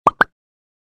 Popup-sound-effect.mp3